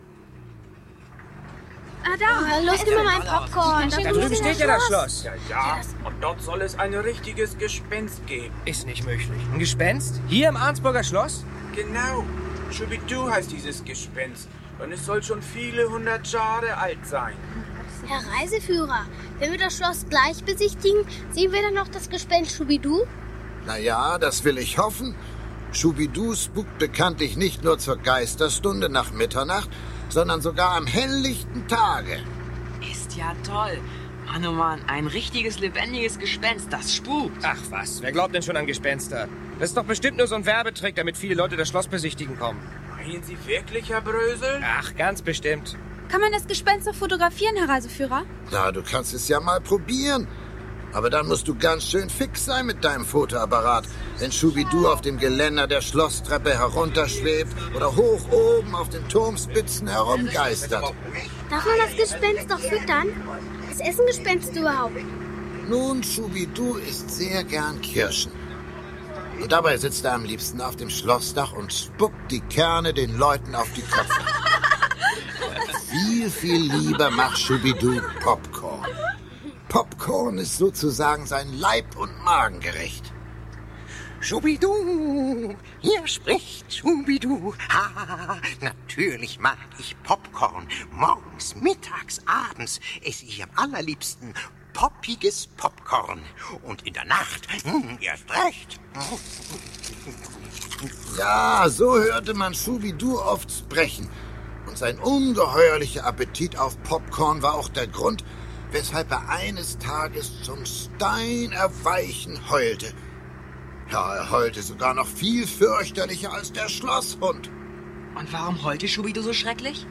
Schubiduu_Uh-Das_Pfiffige_Gespenst-Folge01-Hoerprobe.mp3